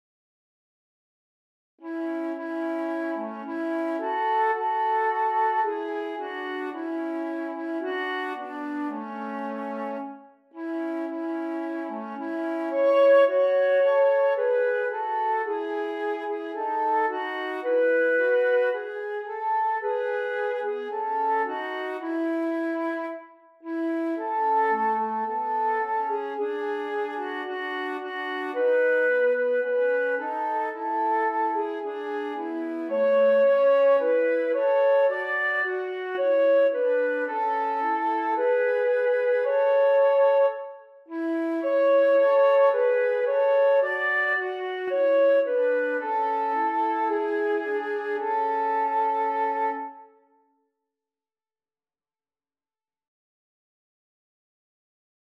Tweestemmig lentelied in A (lager)